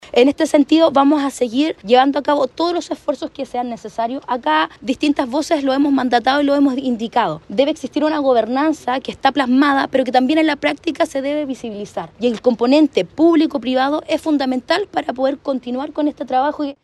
El gobernador regional, Alejandro Santana, y la delegada presidencial, Paulina Muñoz, participaron en la reunión y resaltaron la importancia de un trabajo coordinado entre el sector público y privado para el éxito de la implementación de la estrategia.
delegada-reg.mp3